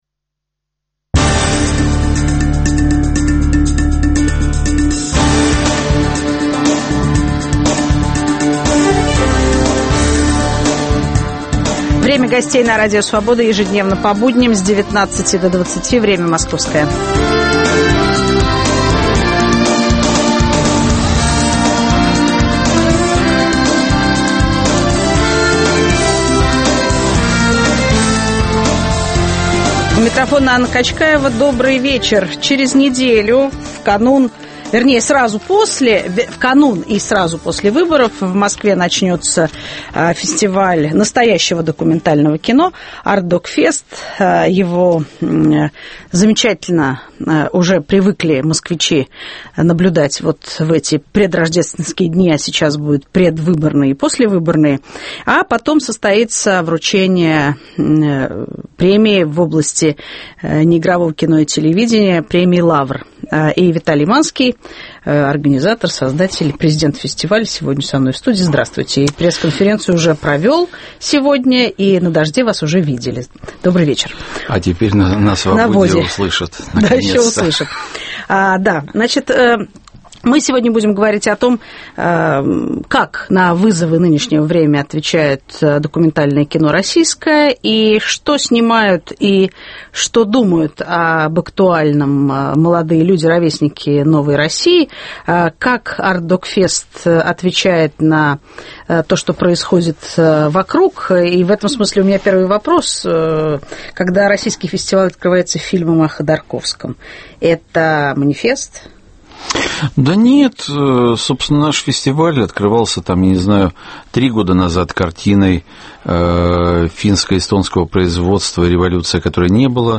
Накануне АРТДок Феста и вручения национальной премии "Лавр" в области документального кино - в студии президент фестиваля Виталий Манский.